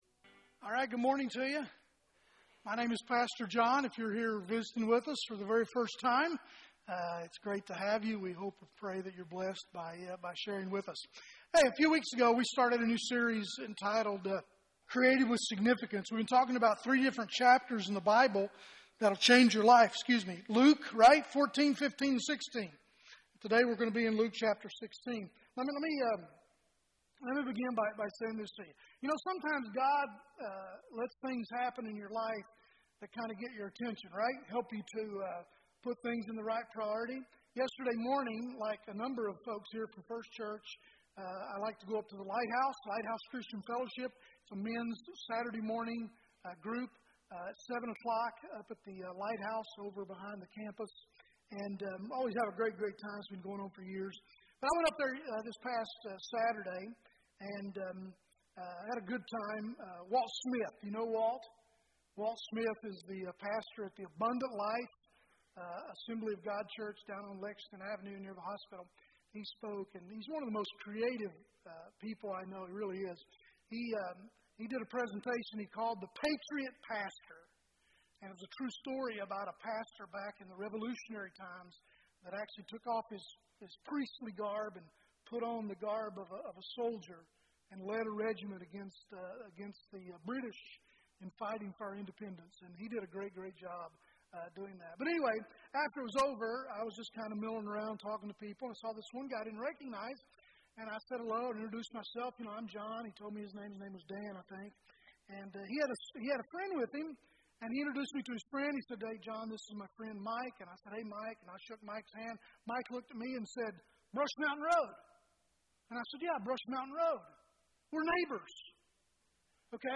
Sermon Description